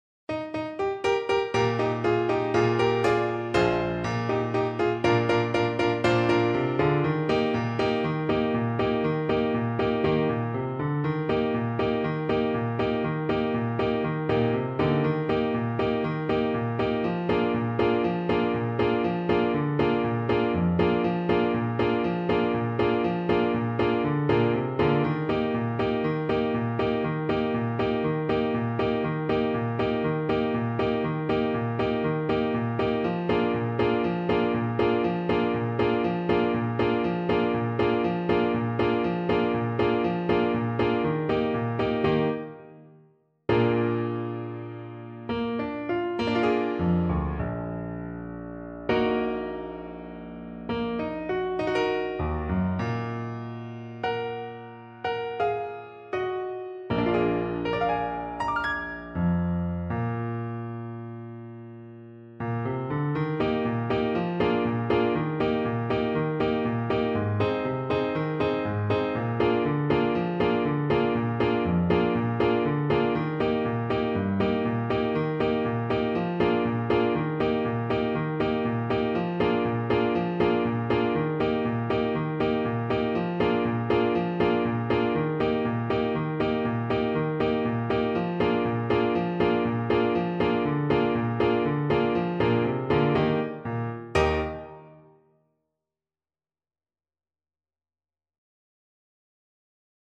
2/4 (View more 2/4 Music)
Mariachi style =c.120